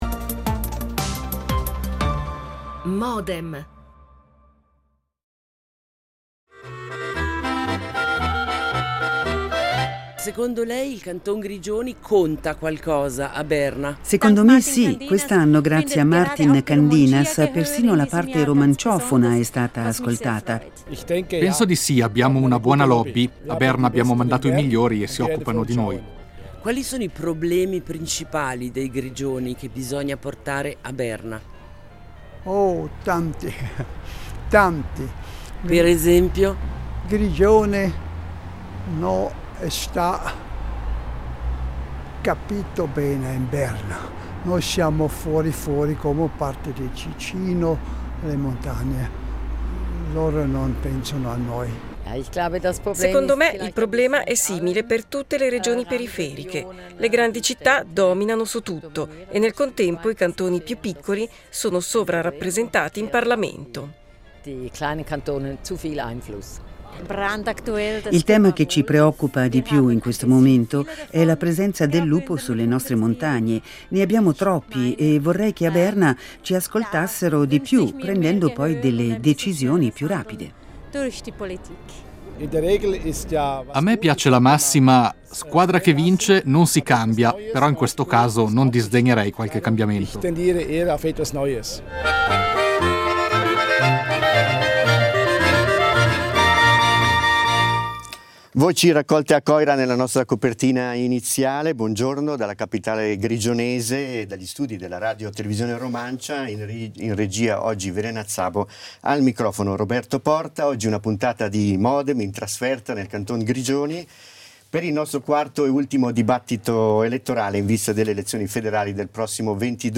Il dibattito in vista del 22 ottobre
L'attualità approfondita, in diretta, tutte le mattine, da lunedì a venerdì